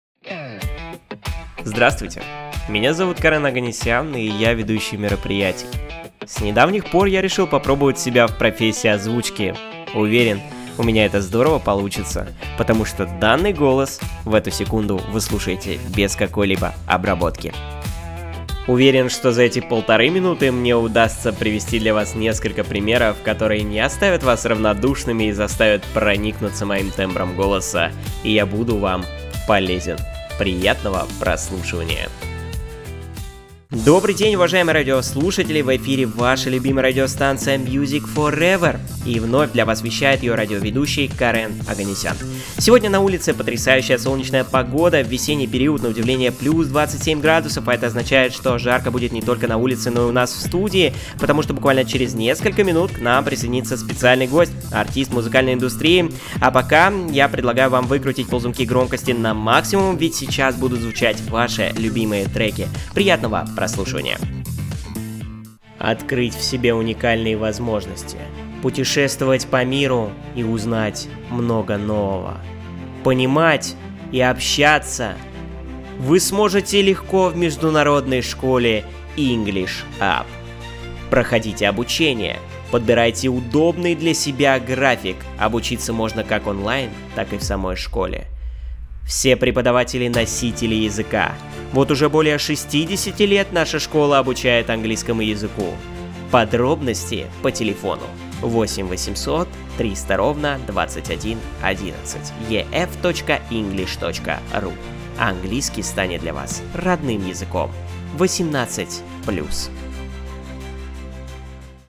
Пример звучания голоса
Муж, Рекламный ролик/Молодой
Студийный Микрофон AT2020